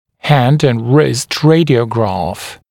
[hænd ənd rɪst ‘reɪdɪəugrɑːf] [-græf][хэнд энд рист ‘рэйдиоугра:ф] [-грэф]рентгенограмма руки и кисти